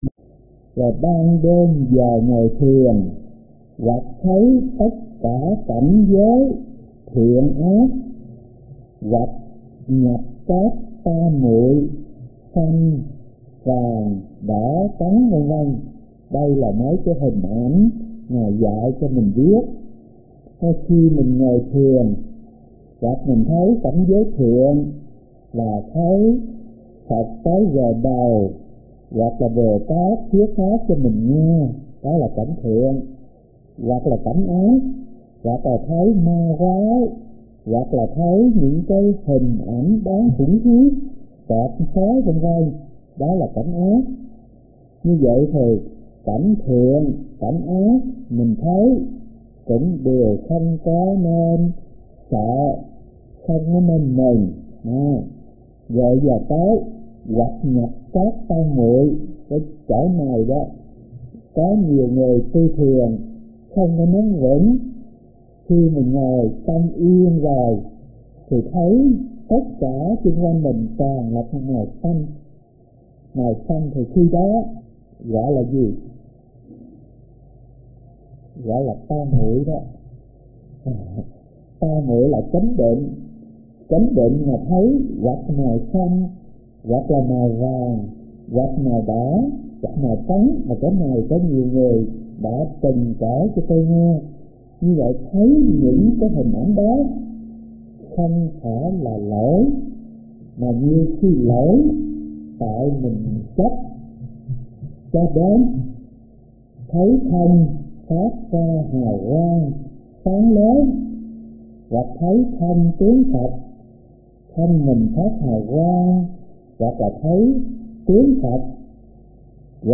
Kinh Giảng Luận Tối Thượng Thừa - Thích Thanh Từ